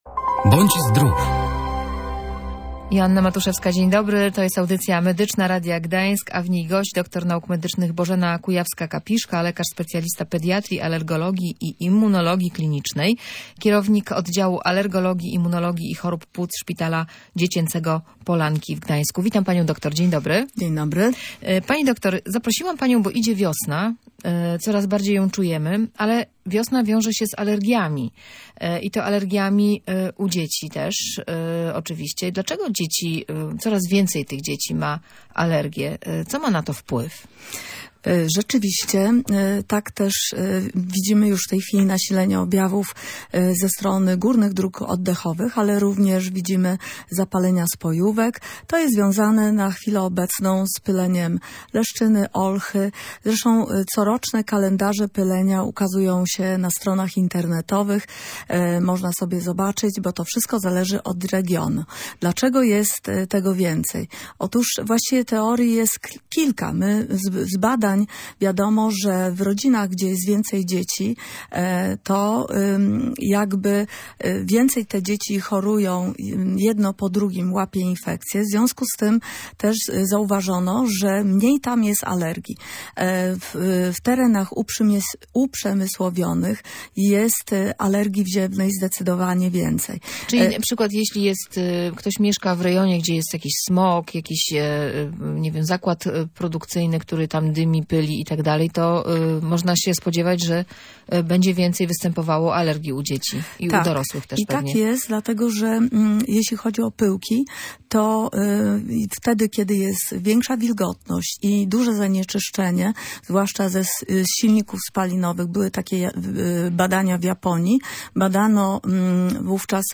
W medycznej audycji „Bądź Zdrów”